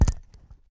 sfx_mic_drop.ogg